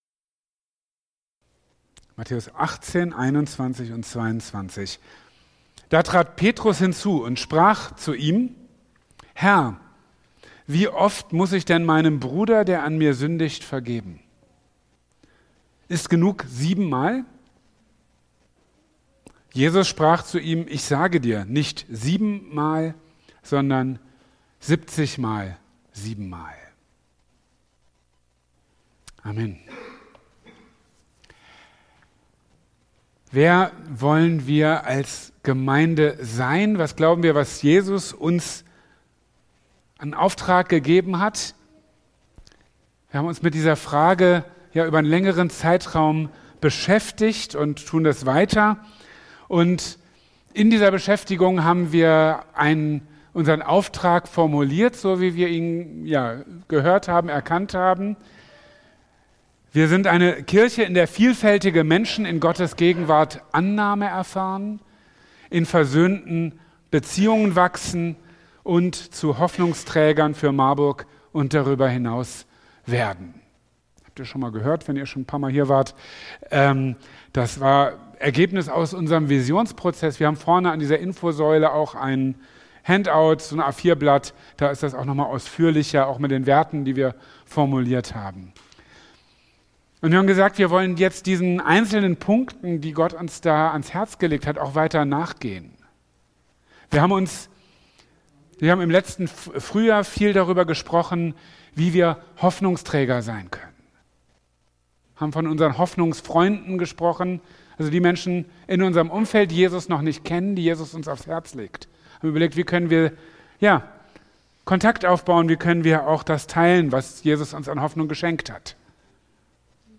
In seiner Predigt vom 22.